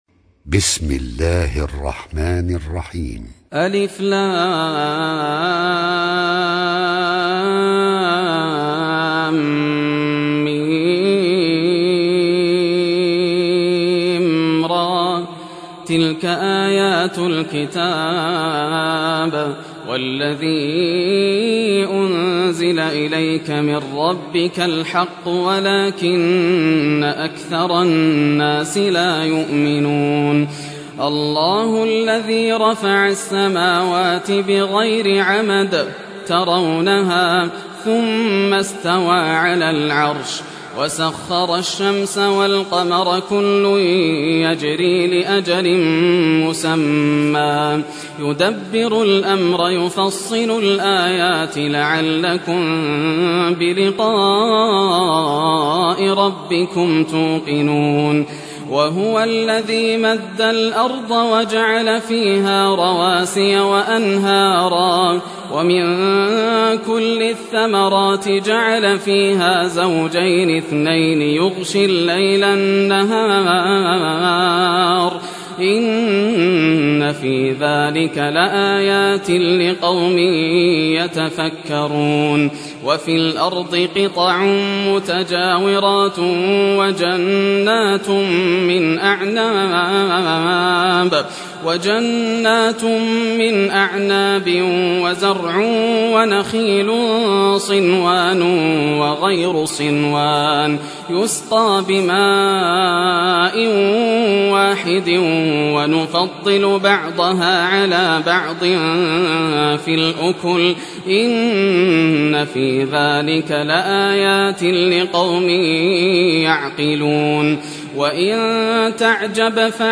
Surah Ar Raad Recitation by Yasser Al Dosari
Surah Ar Raad, listen or play online mp3 tilawat / recitation in Arabic in the beautiful voice of Sheikh Yasser Al Dosari.